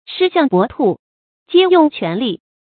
注音：ㄕㄧ ㄒㄧㄤˋ ㄅㄛˊ ㄊㄨˋ ，ㄐㄧㄝ ㄩㄥˋ ㄑㄨㄢˊ ㄌㄧˋ